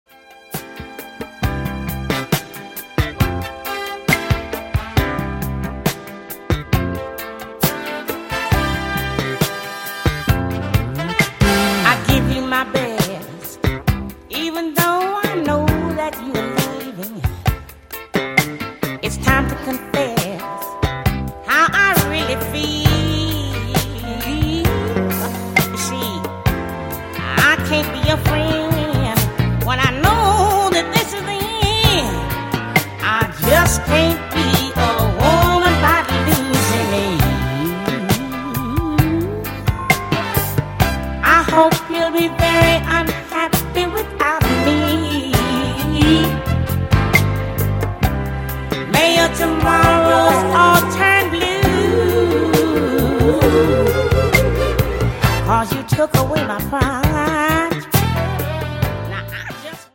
funky
the bluesy funk